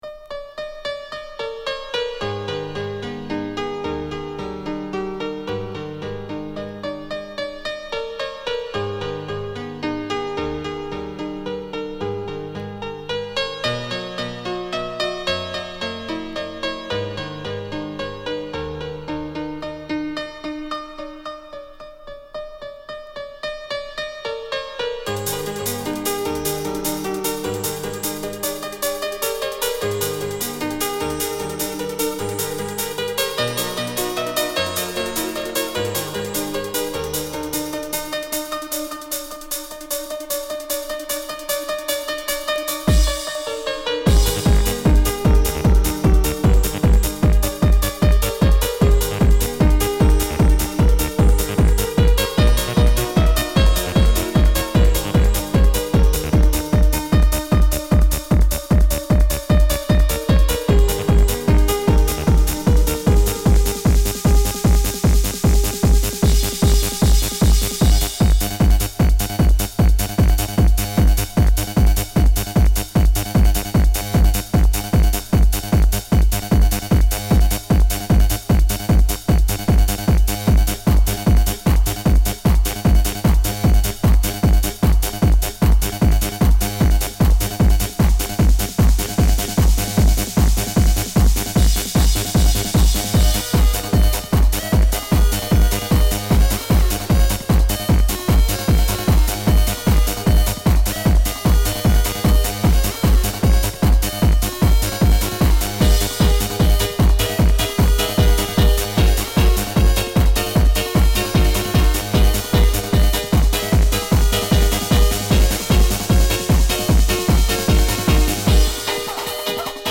bethoven- techno remix - classical - fur elise.mp3